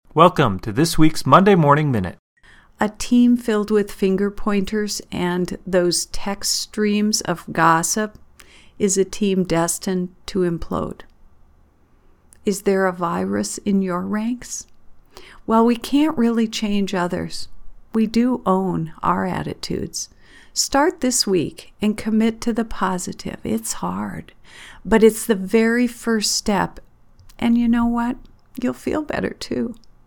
Studio version: